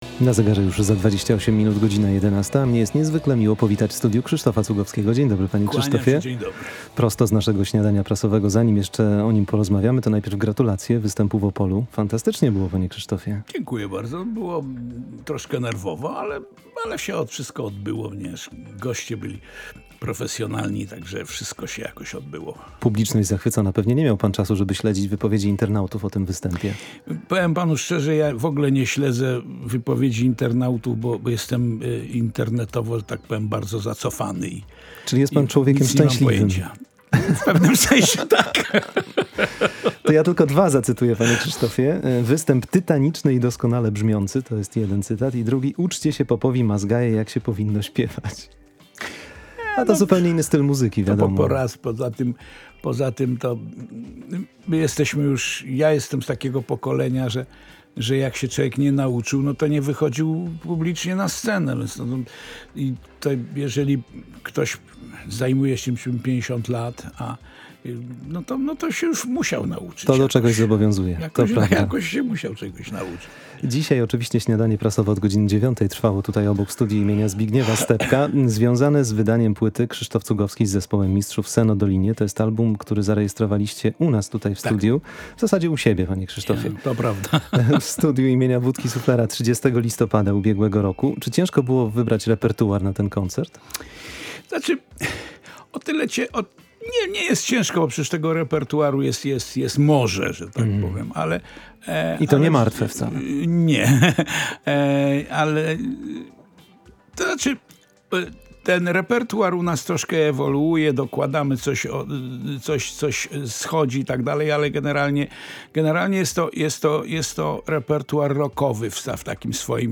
Gościem „Przedpołudnika” był Krzysztof Cugowski.